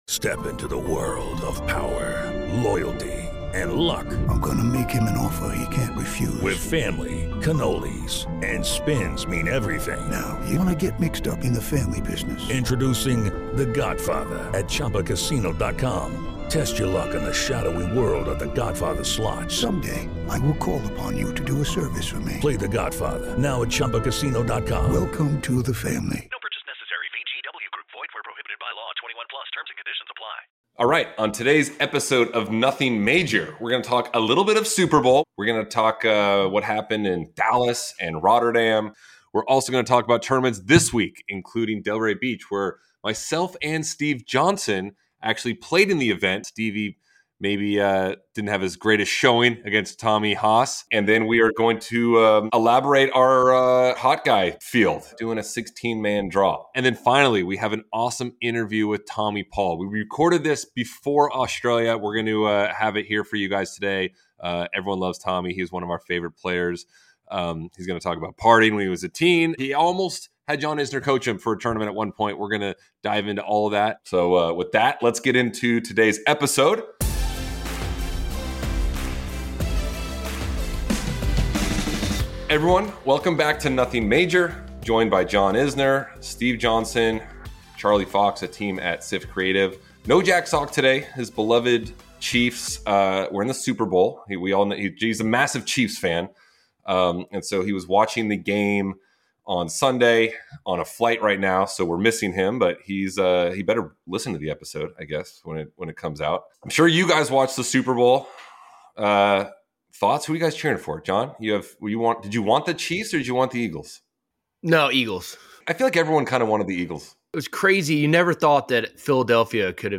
19:39 Tommy Paul Interview